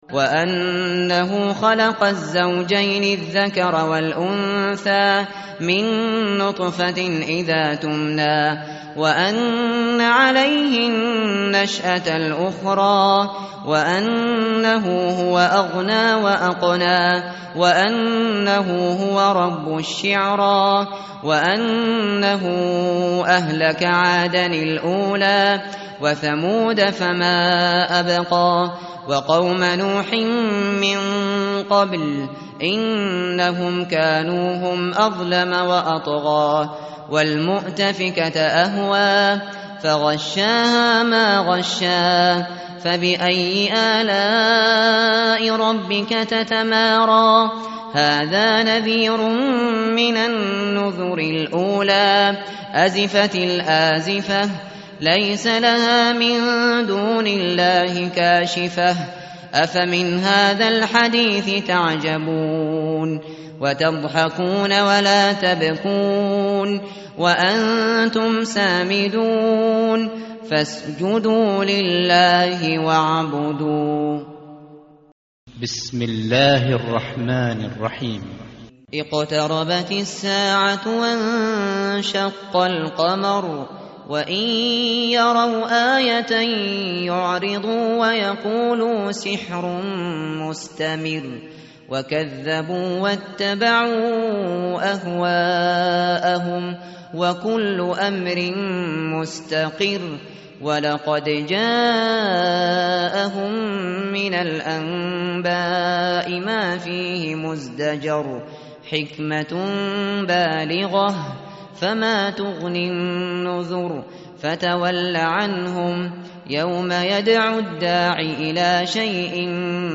tartil_shateri_page_528.mp3